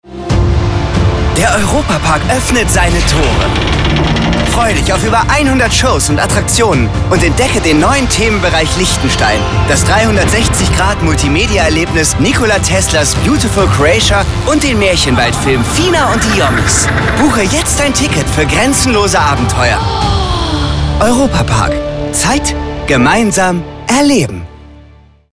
Junge & frische Werbesprecher:innen – New Voices
junger Werbesprecher mit urbaner Coolness
Stimmcharakter:         cool, frech, markant, locker